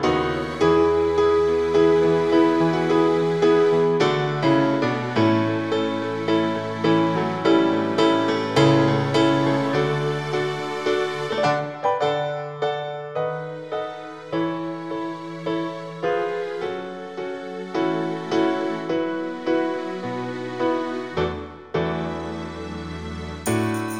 no Backing Vocals Soundtracks 3:26 Buy £1.50